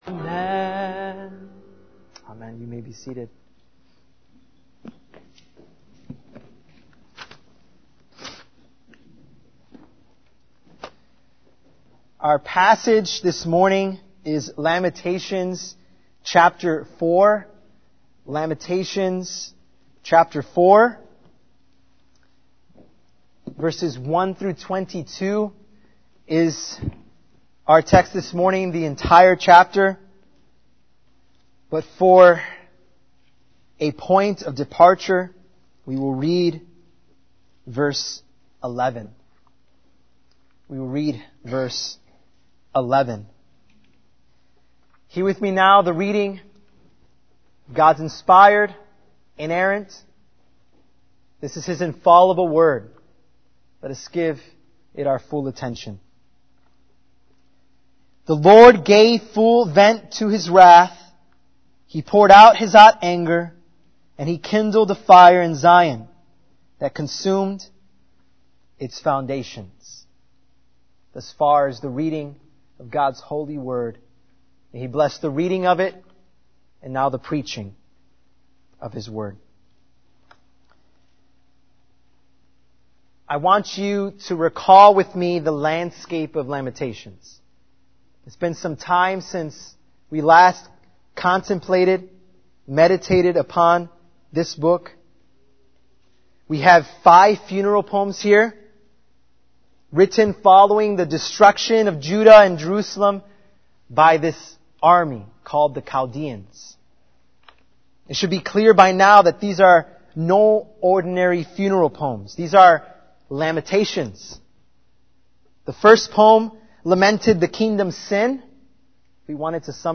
Guest Sermons